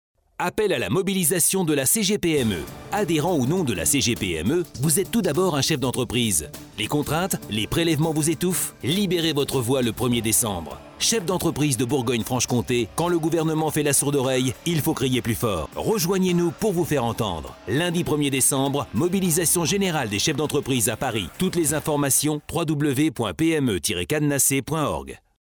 spot_CGPME 241114.mp3 (994.88 Ko)